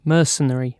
Ääntäminen
IPA : /ˈmɜː(ɹ).sən.ə.ɹi/